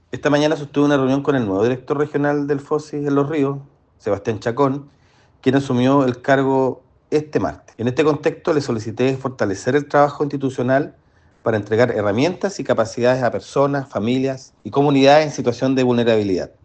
El delegado Presidencial, Jorge Alvial, confirmó al nuevo titular en el cargo y sostuvo que es necesario reforzar el trabajo que desarrolla el Fosis en la zona.